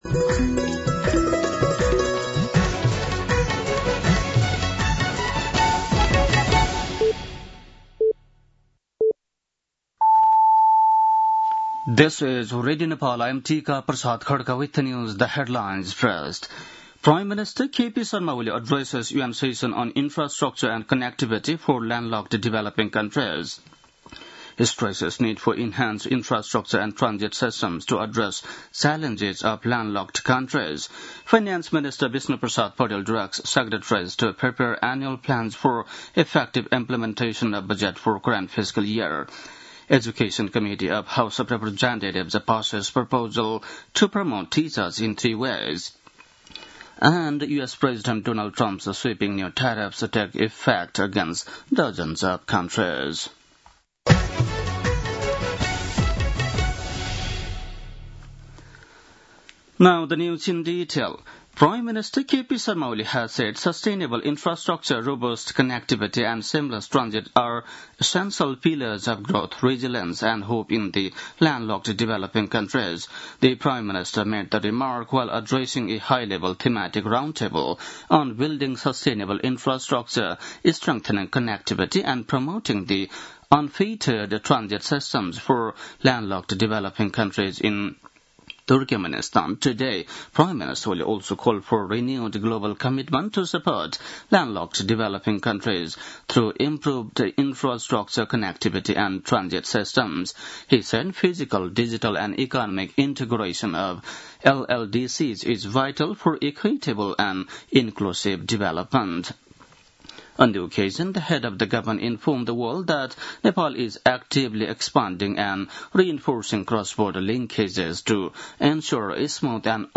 बेलुकी ८ बजेको अङ्ग्रेजी समाचार : २२ साउन , २०८२